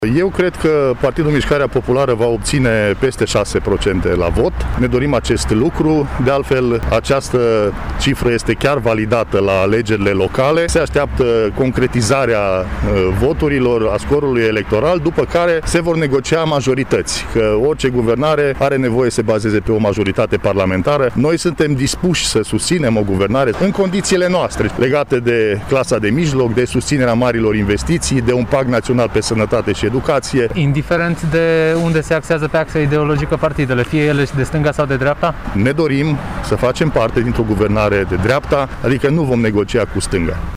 Aceasta a fost declarația președintelui filialei mureșene a Partidului Mișcarea Populară, încrezător datorită rezultatelor obținute la alegerile locale din 27 septembrie.
Deputatul de Mureș și candidat pentru un nou mandat parlamentar, Marius Pașcan.